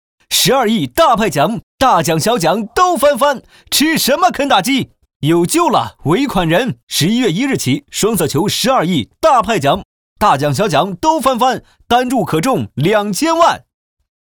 男10号
双色球(促销叫卖广告)